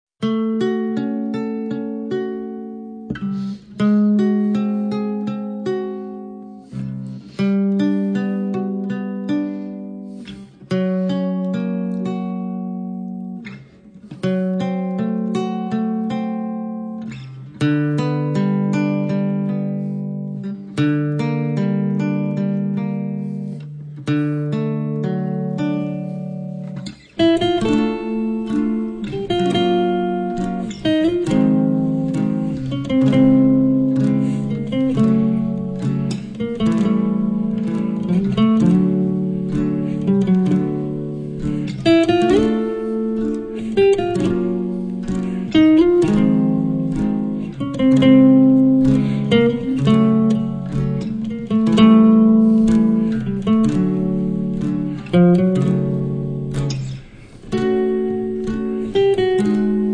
chitarra
il dolce brano